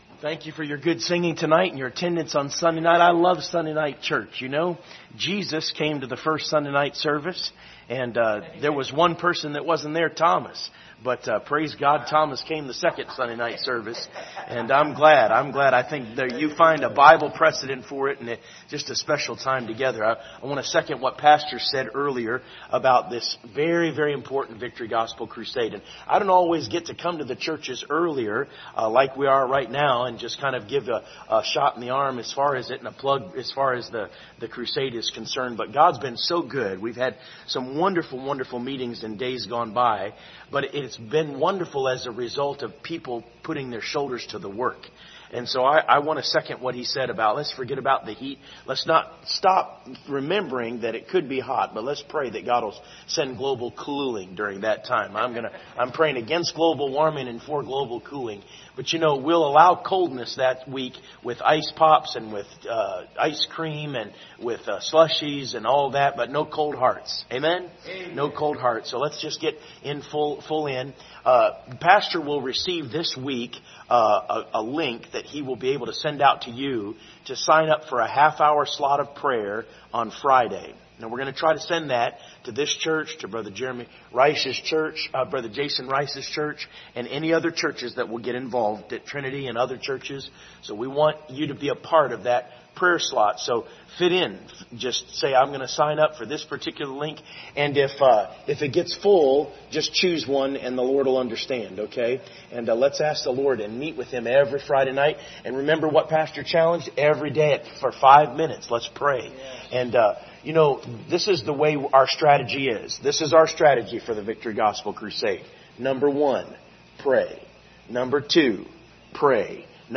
Acts 26:13-18 Service Type: Sunday Evening Topics